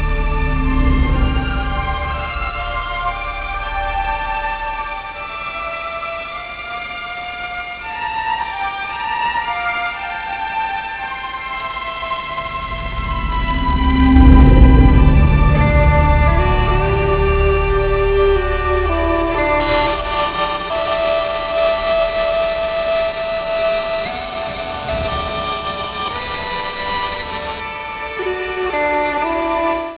Colonna sonora
Original track music: